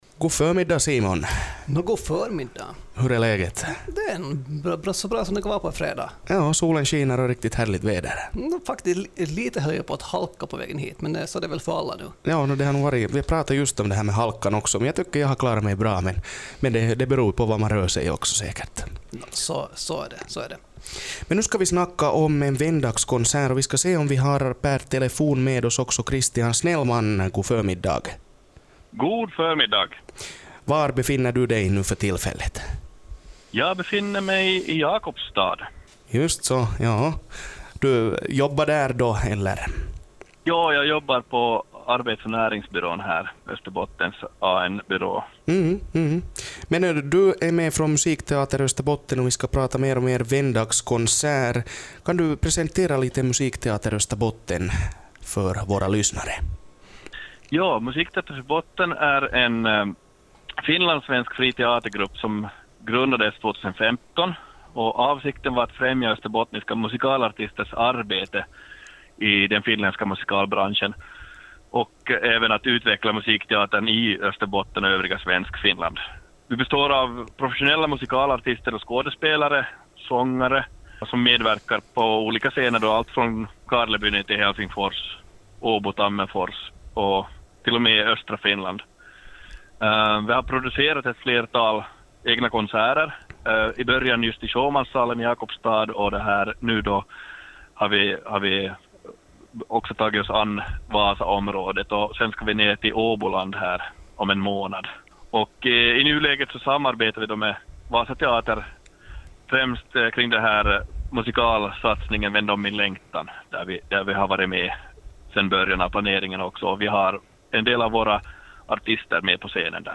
intervjuas